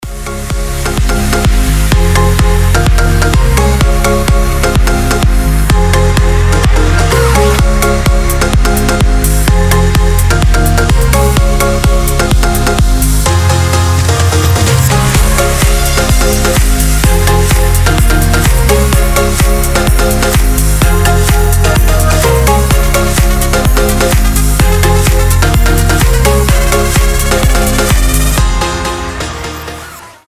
• Качество: 320, Stereo
громкие
EDM
без слов
future house
Просто классная хаус-музыка без слов